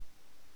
captivating and melodic techno